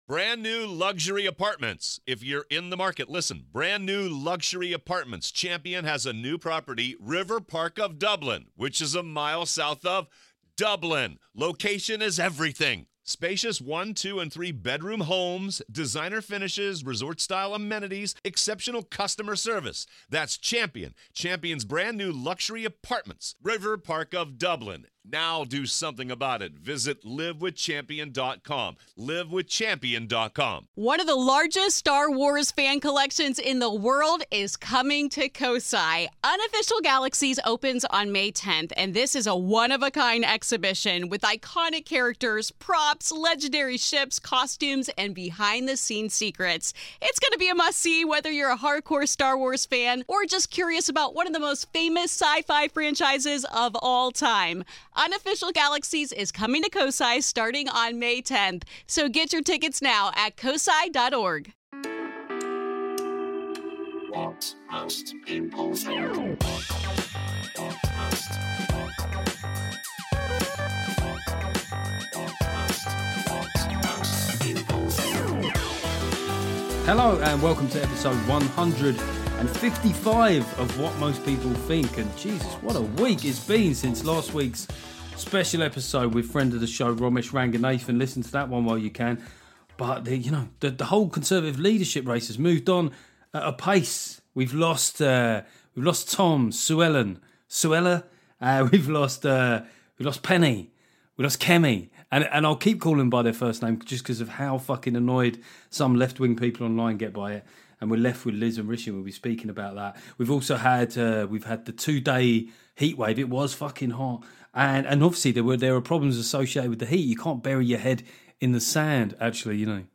After I have a serious rant about both the Tory leadership race & Emily Thornberry, Seann Walsh returns for another lovely comedy chat.